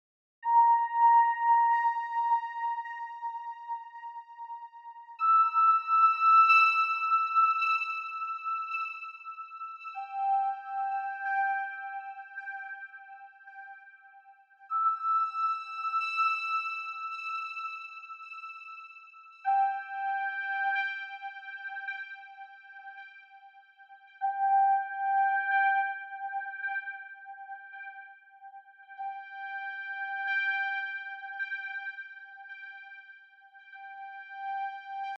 Octave 4
Une note sur 13 : Tous les 6 temps et demi
Durée : 1 temps et demi
Cette méthode est redoutable pour créer des nappes éthérées et instables qui pourraient être facilement intégrables dans des compositions électros plus conventionnelles.